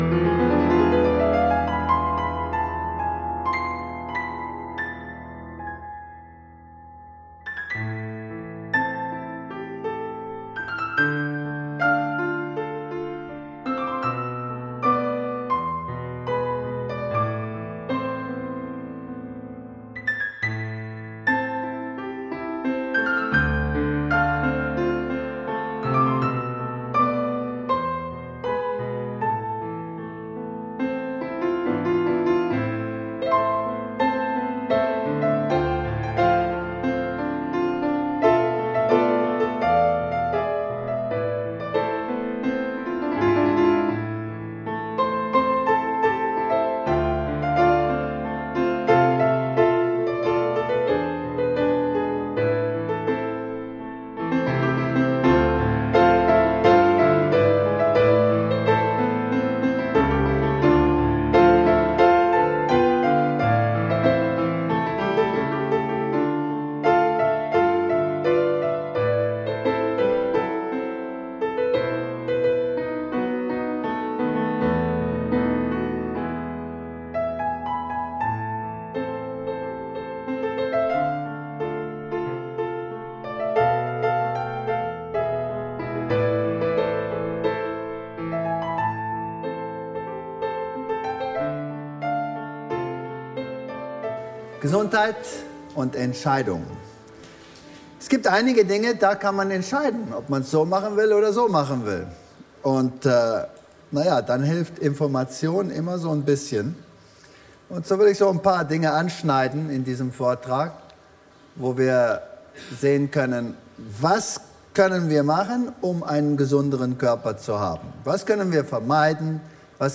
Dieser Vortrag nimmt nicht nur Nahrungsmittel-Zusatzstoffe wie Geschmacksverstärker und besonders Süßstoff unter die Lupe, sondern geht auch den zahlreichen Auswirkungen von Stoffen nach, die dem Leben oft freiwillig zugesetzt werden: Alkohol, Nikotin...